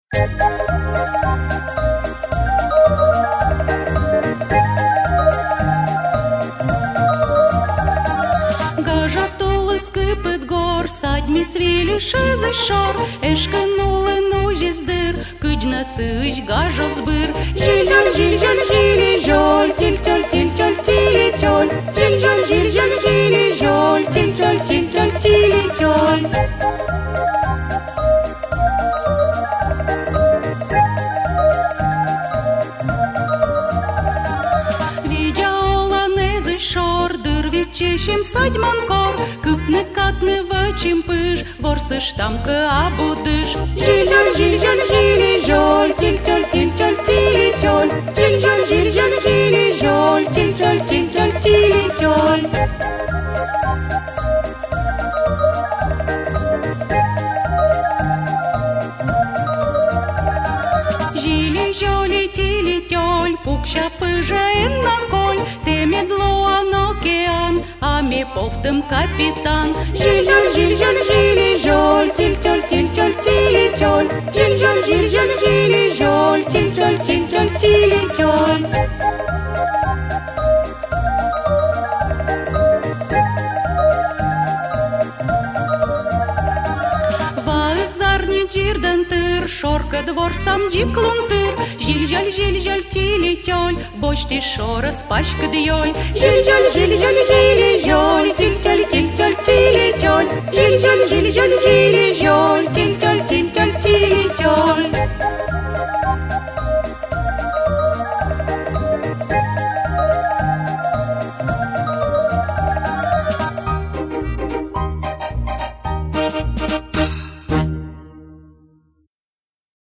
Сьыланкыв